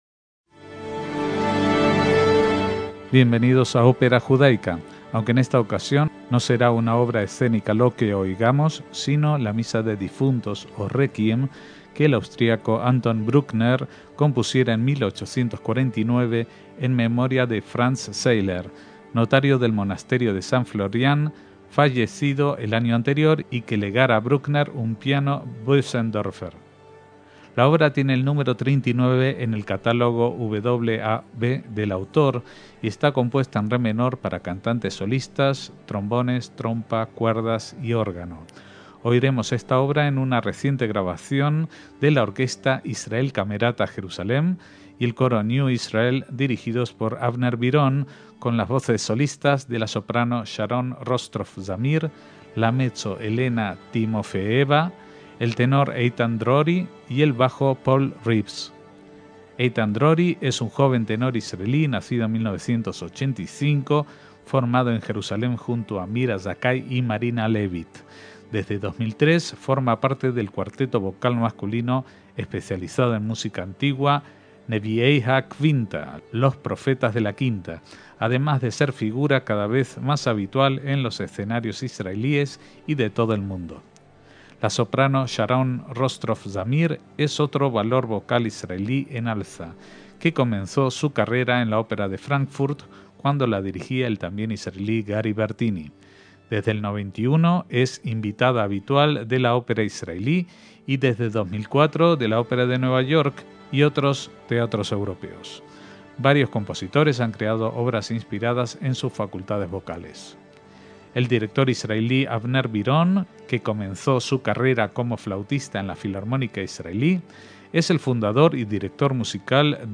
una misa de difuntos
voces solistas
soprano
mezzo
tenor
bajo